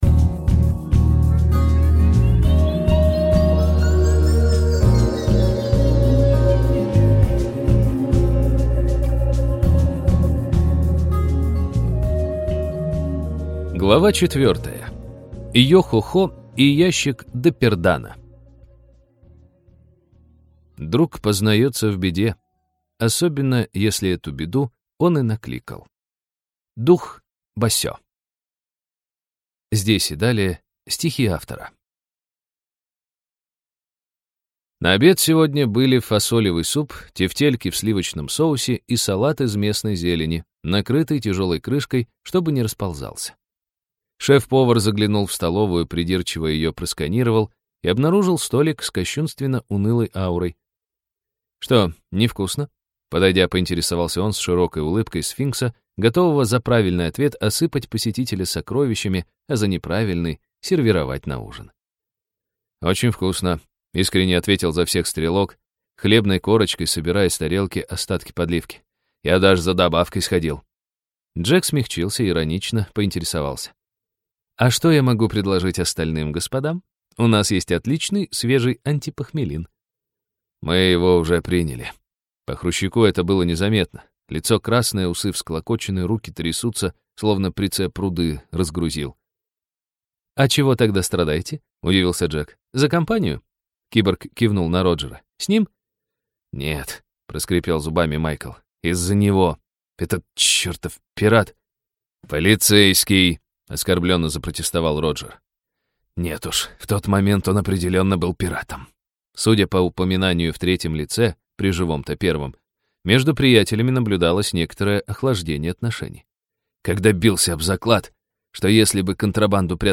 Аудиокнига Киберканикулы. Часть 4.
На этой странице выложена часть № 4 аудиокниги «Киберканикулы» по одноименному произведению Ольги Громыко.